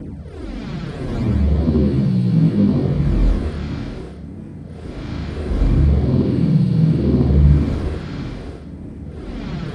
WATERROARS.wav